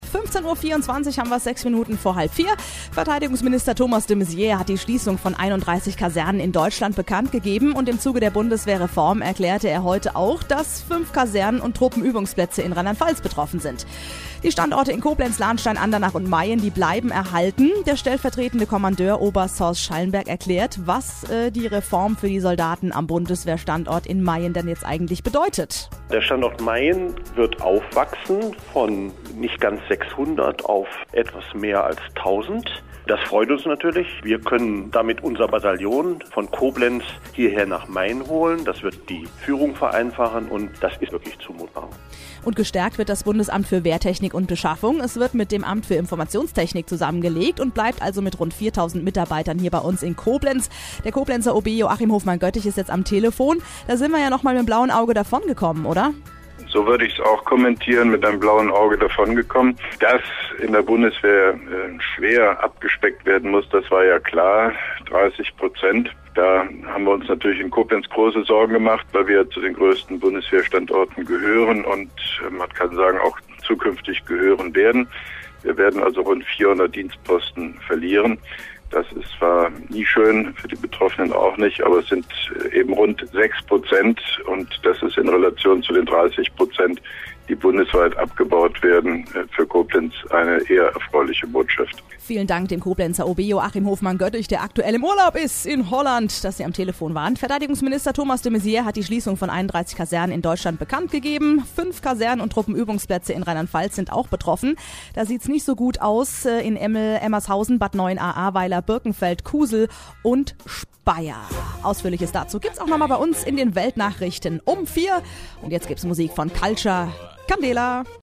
Interviews/Gespräche
u.a. mit Stellungnahme von OB Hofmann-Göttig